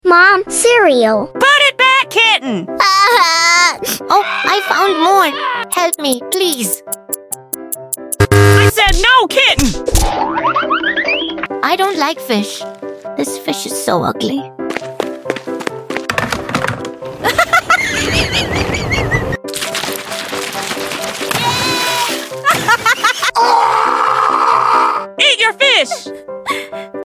Kitten goes splashy for cereal sound effects free download